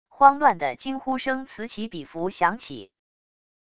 慌乱的惊呼声此起彼伏响起wav音频